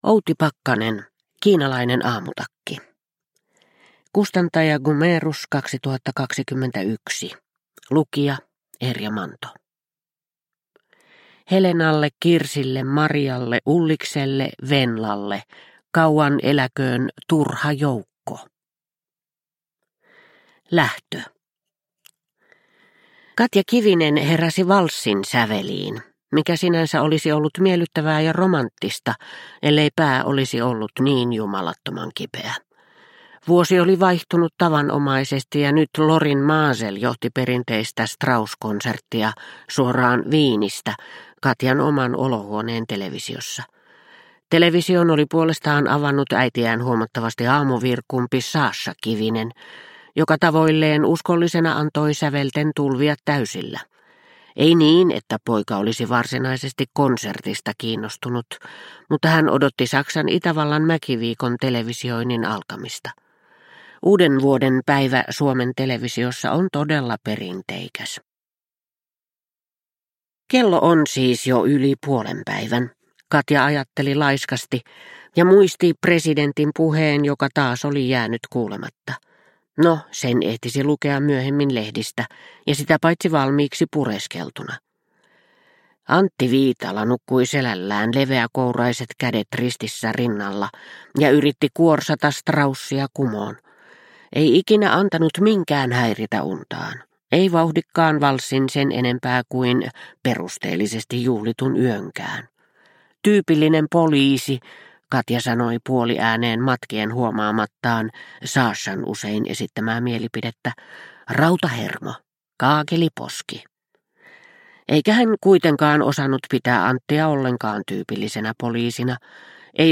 Kiinalainen aamutakki – Ljudbok – Laddas ner